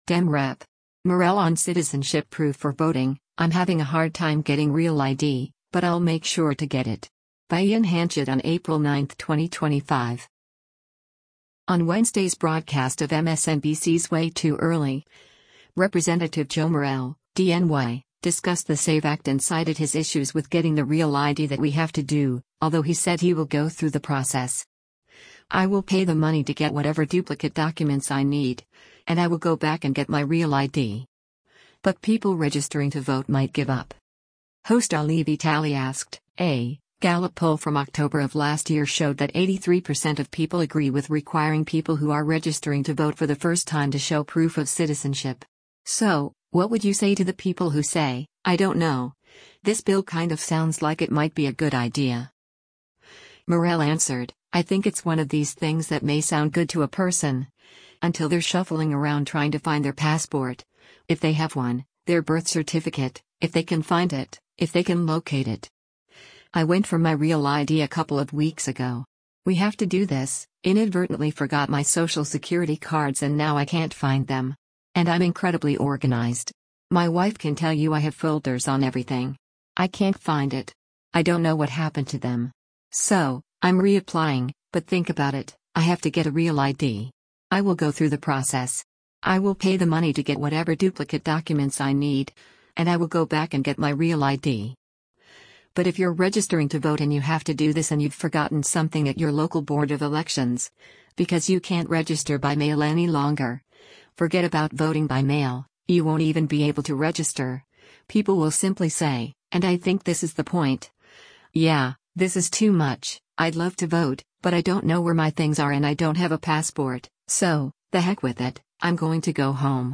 Video Source: MSNBC
On Wednesday’s broadcast of MSNBC’s “Way Too Early,” Rep. Joe Morelle (D-NY) discussed the SAVE Act and cited his issues with getting the REAL ID that “We have to do”, although he said he “will go through the process.